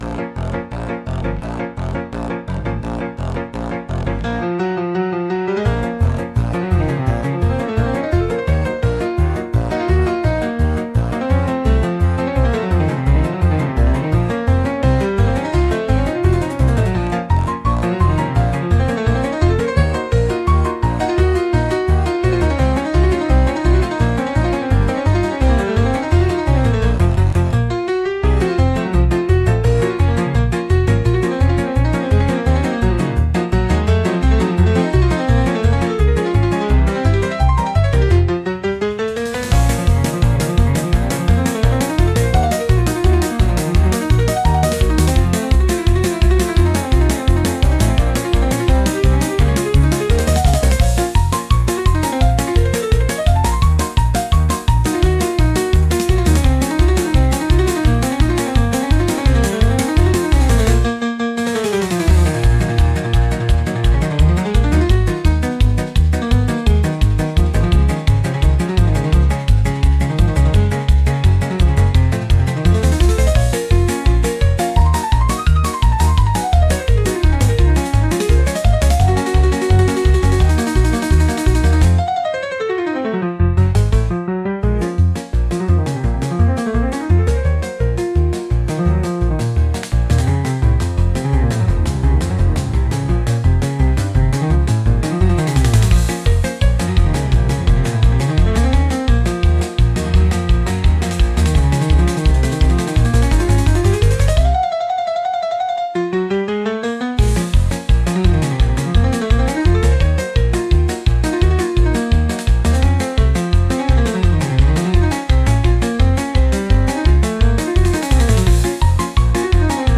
Instrumental / 歌なし
ピアノのメインメロディが、息つく暇もなく疾走する高速ナンバー。
この曲の最大の特徴は、ラストの劇的なスピードダウン。